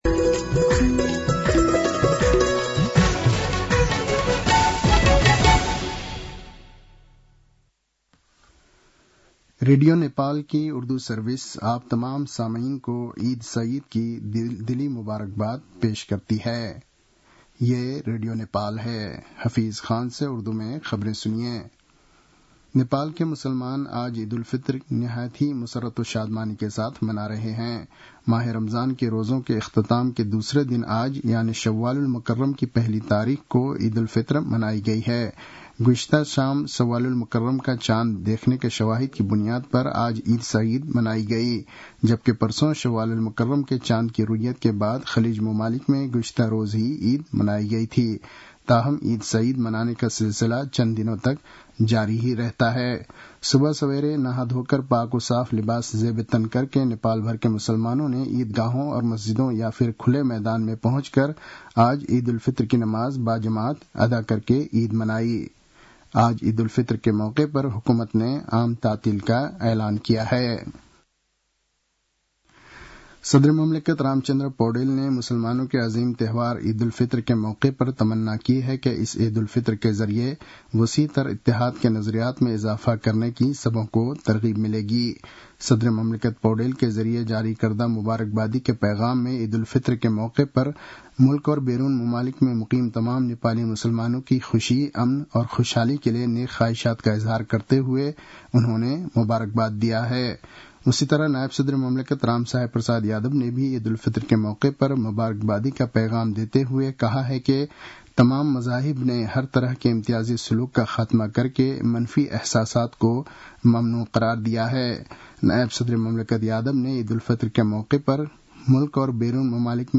उर्दु भाषामा समाचार : १८ चैत , २०८१